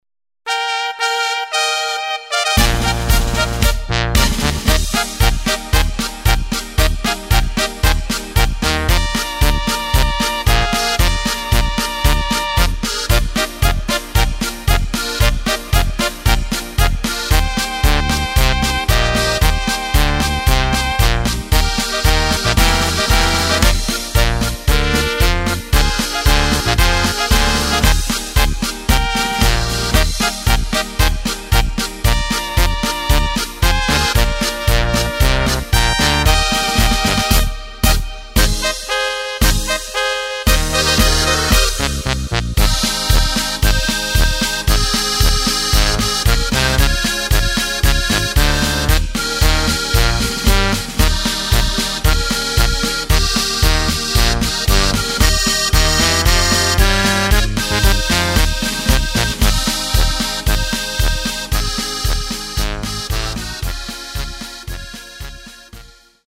Takt:          2/4
Tempo:         114.00
Tonart:            Eb
Schöne Polka im Oberkrainer Stil!
Playback mp3 Demo